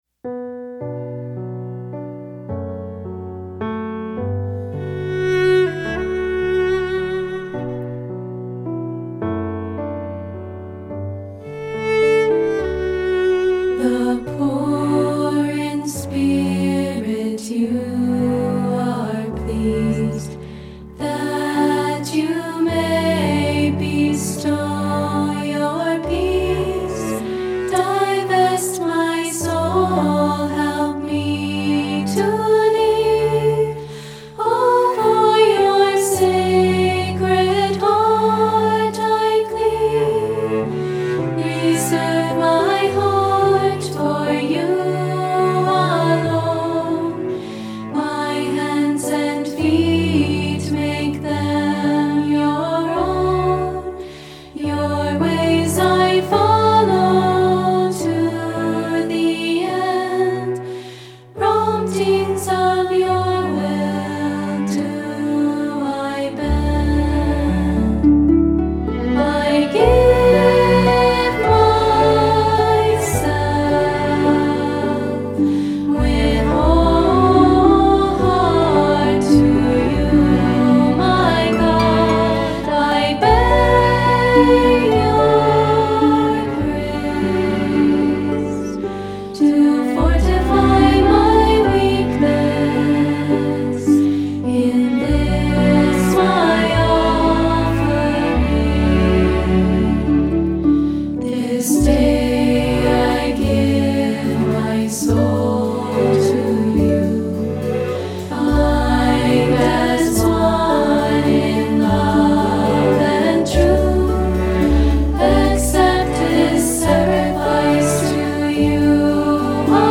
Musical Meditation